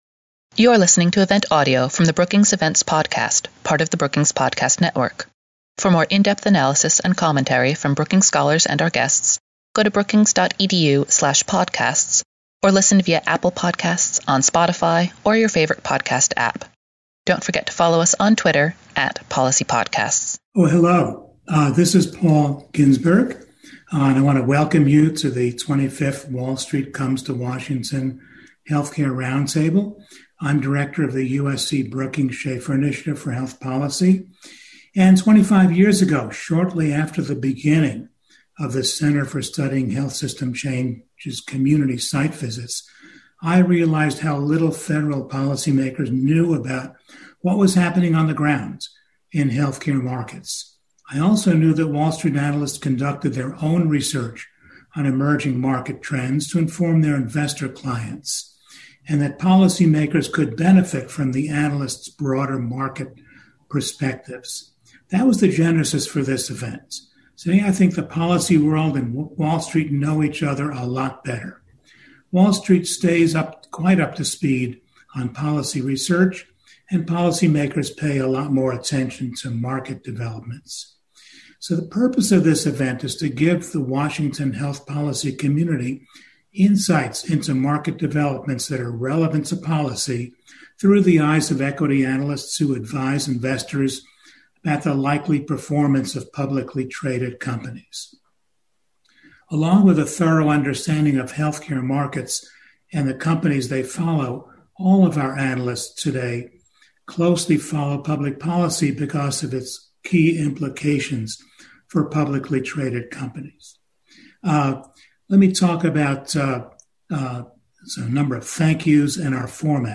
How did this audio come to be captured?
On Friday, April 9, the USC-Brookings Schaeffer Initiative for Health Policy will host the 25th Wall Street Comes to Washington Health Care Roundtable as a webinar.